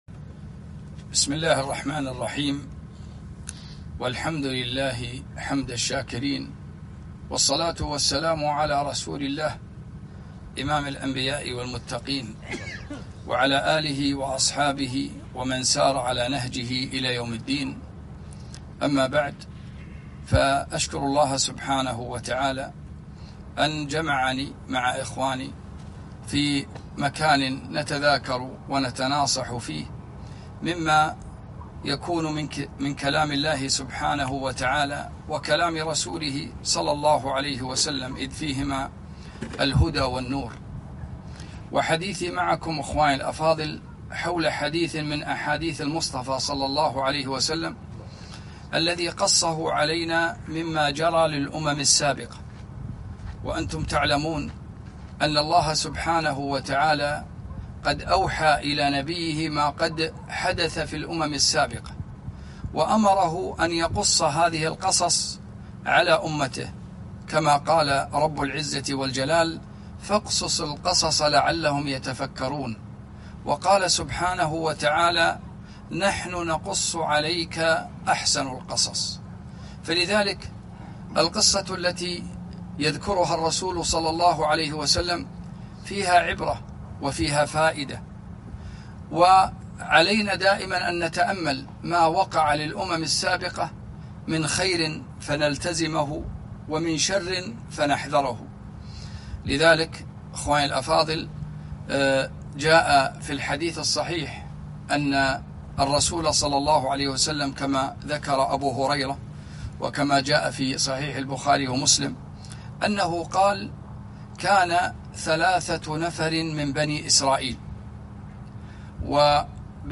كلمة - من قصص الأمم السابقة